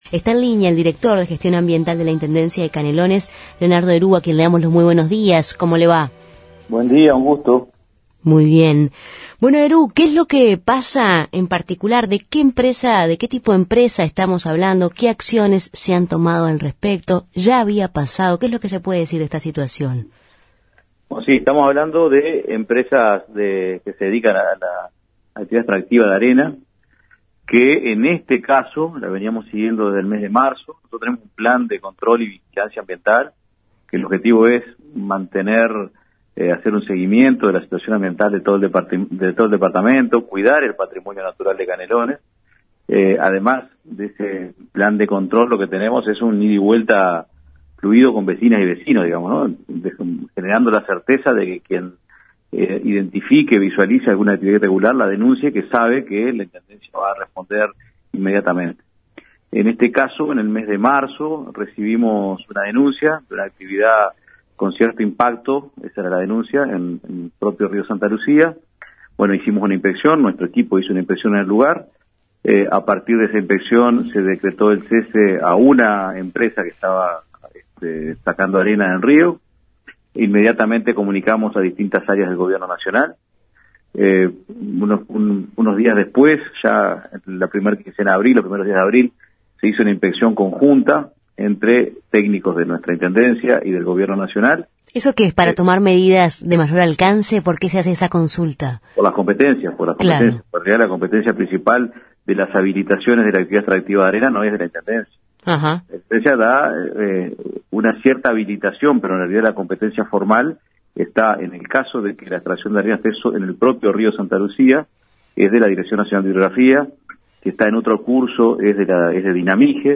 La decisión fue adoptada por el intendente Yamandú Orsi y así lo explicó el director de Gestión Ambiental de la Intendencia de Canelones,  Leonardo Herou, en Justos y pecadores